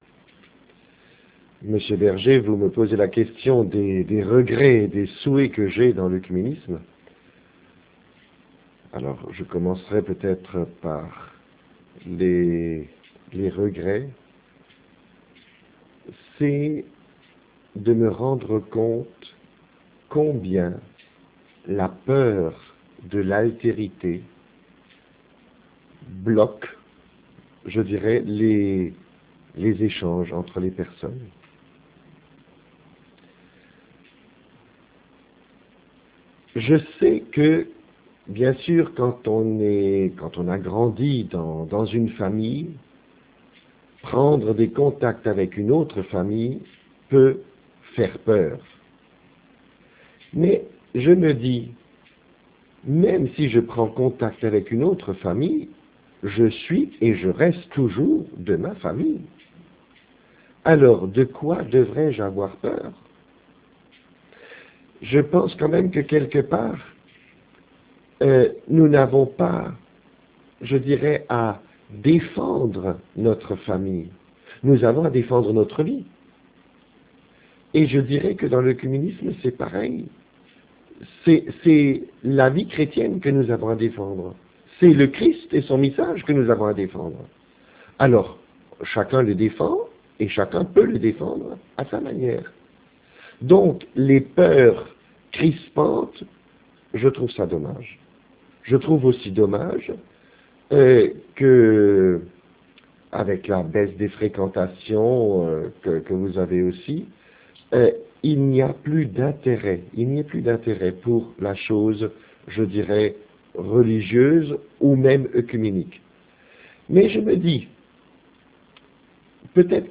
Interview - Regrets